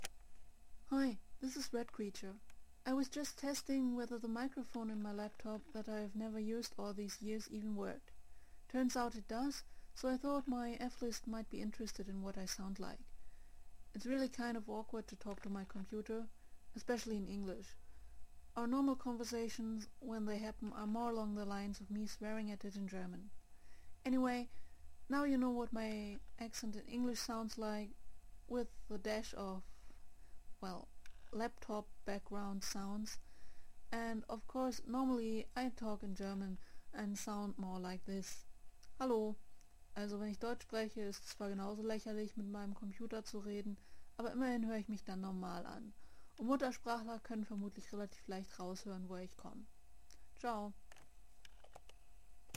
I mean, my English retains a foreign accent too (English started only in fifth grade when I went to school, not in elementary school or even kindergarten as they do it now) but it is not that bad, I don't think. (A few years back I recorded a short sample of me speaking English and German, so you can judge